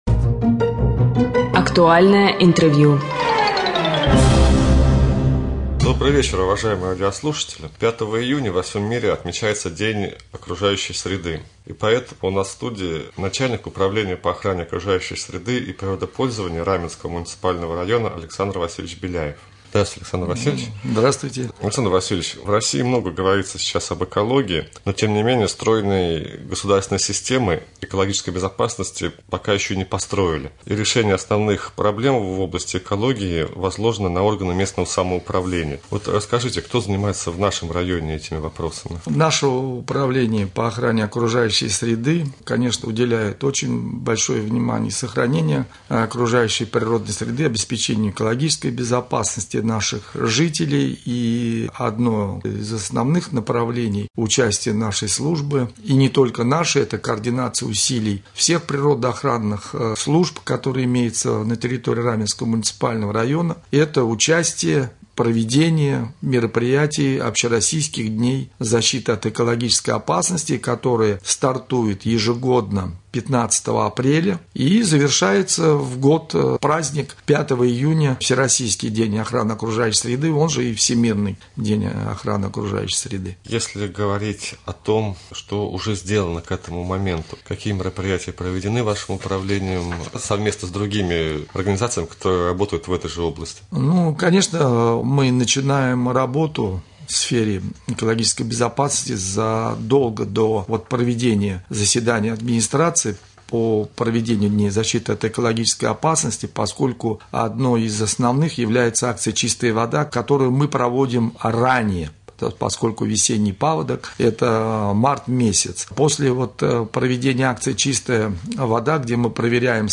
Гость студии начальник районного управления по охране окружающей среды и природопользованию Александр Васильевич Беляев.